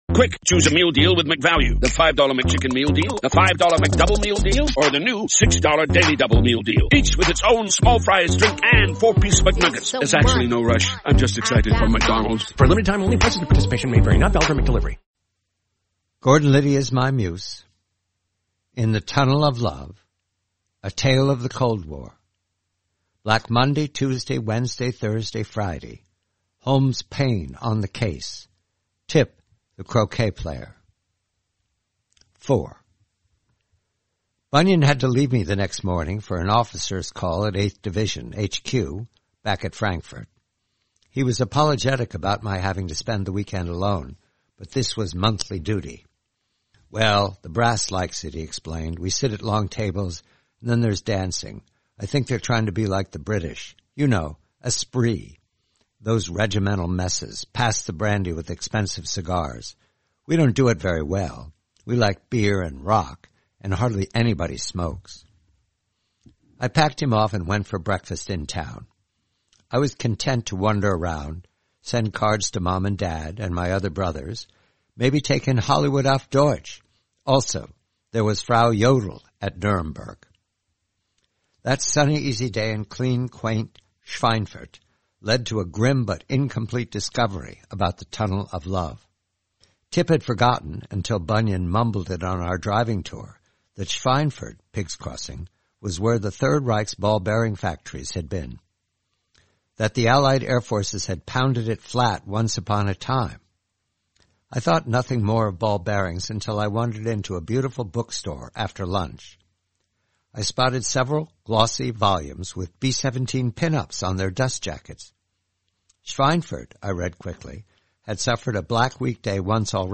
4/6: "In the Tunnel of Love," a story from the collection, "Gordon Liddy is My Muse," by John Calvin Batchelor. Read by John Batchelor.